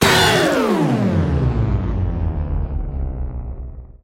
На этой странице собраны звуки резкого отключения электричества – от тихого щелчка выключателя до гула пропадающего напряжения в сети.
Отключили свет рубильником